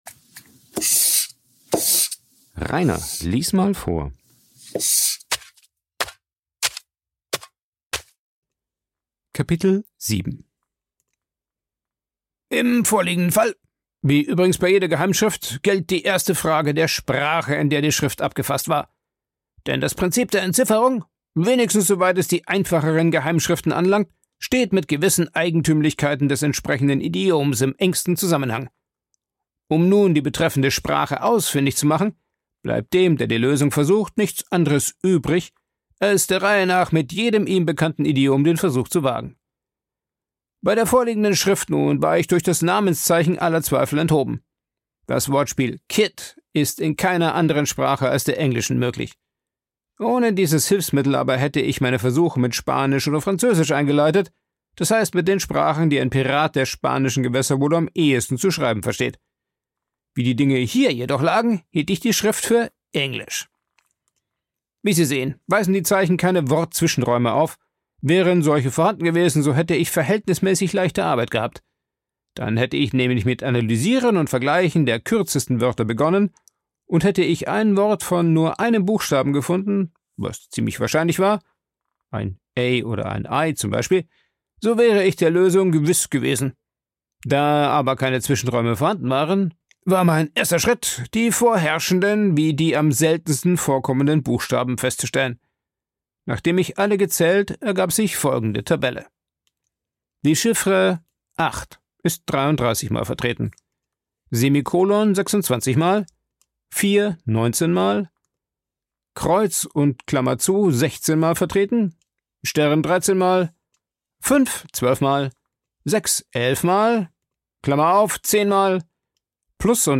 Ein Vorlese Podcast
Coworking Space Rayaworx, Santanyí, Mallorca.